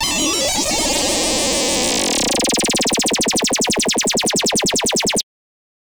Glitch FX 25.wav